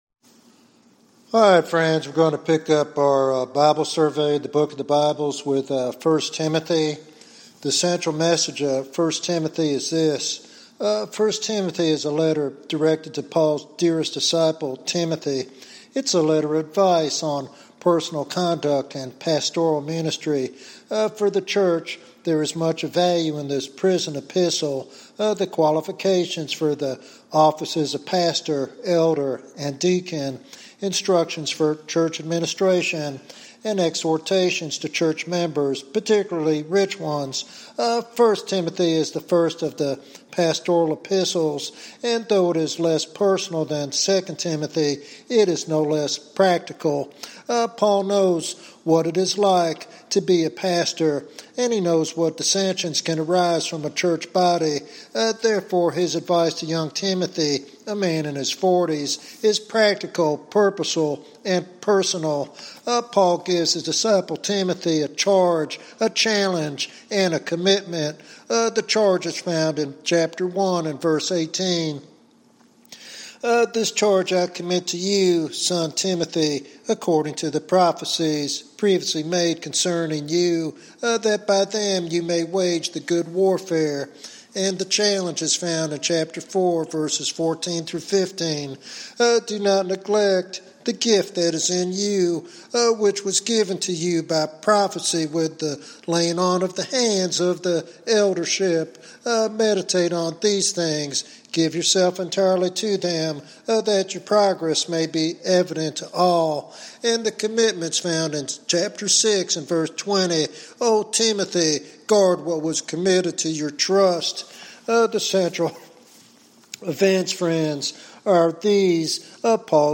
The sermon explores practical applications for today's believers to remain steadfast in faith, uphold church order, and respond to challenges with godly wisdom.